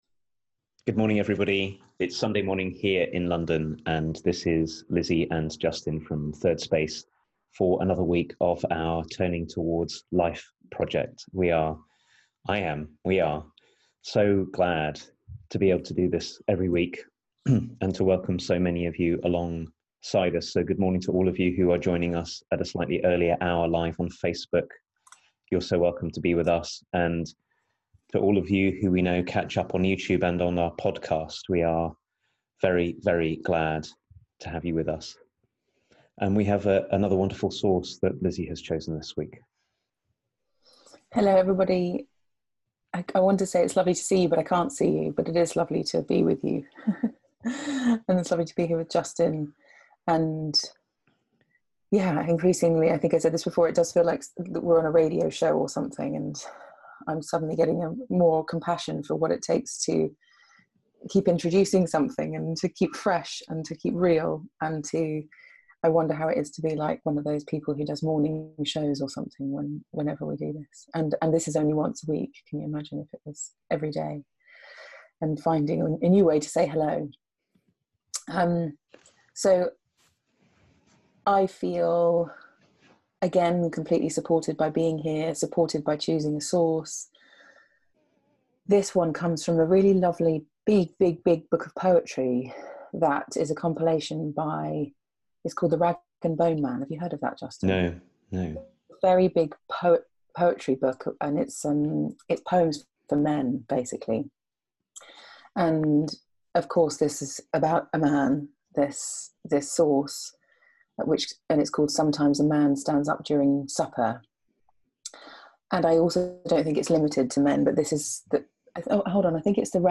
weekly live 30 minute conversation